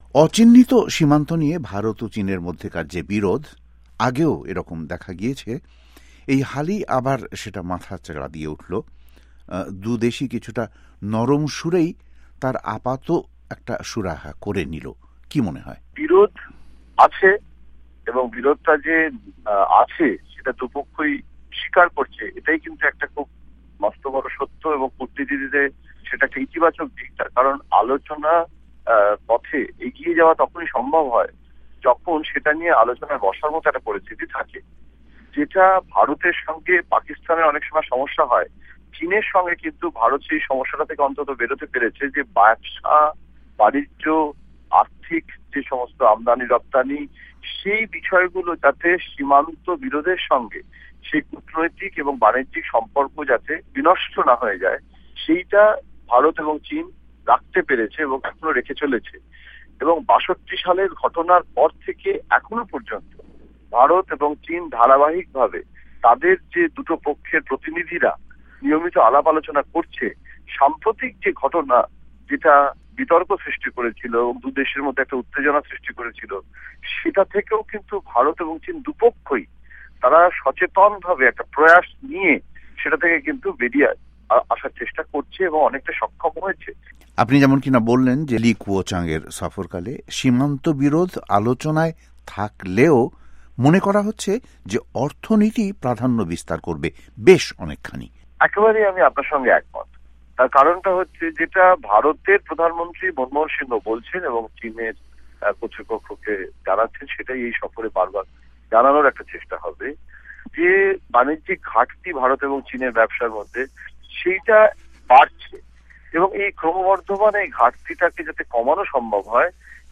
ওয়াশিংটন স্টুডিও থেকে তাঁর সঙ্গে কথা বলেন